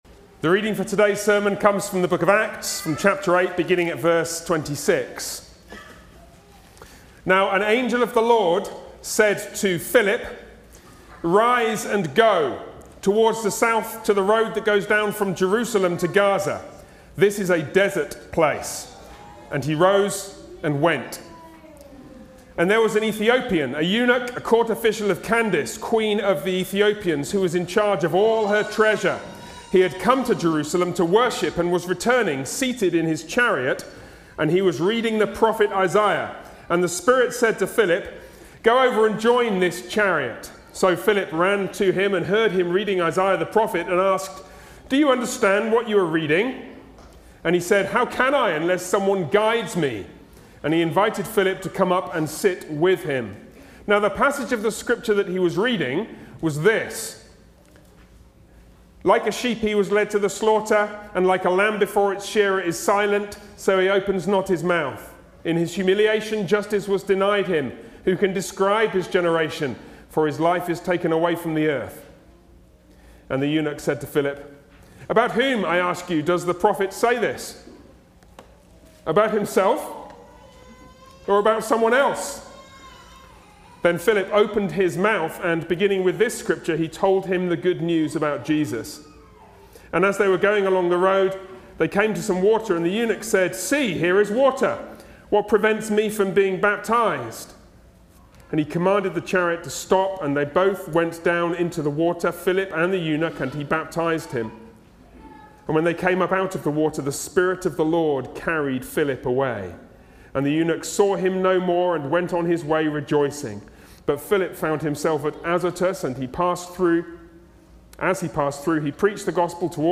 Series: Sermons on Acts Passage: Acts 8:26-40 Service Type: Sunday worship